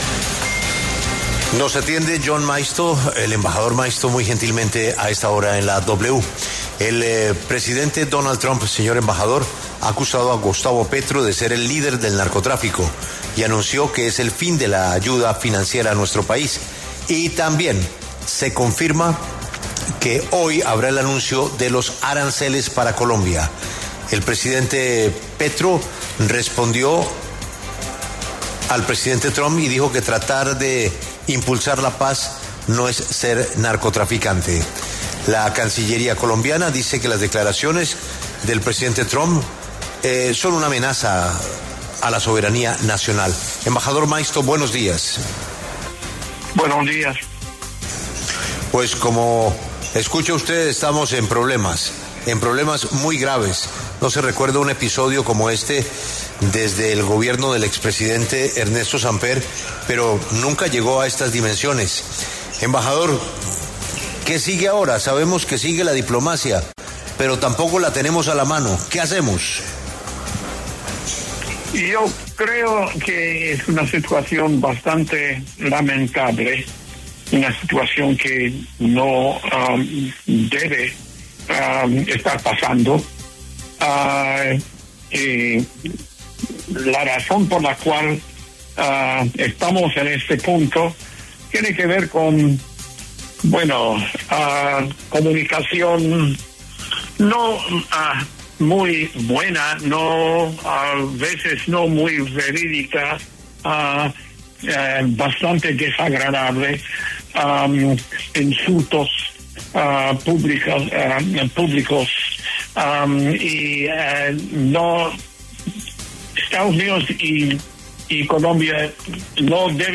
Jorge Rojas, ex viceministro de Asuntos Exteriores de Colombia, pasó por los micrófonos de La W sobre el choque entre Trump y Petro luego de que el mandatario estadounidense acusara a Petro de ser “líder del narcotráfico”.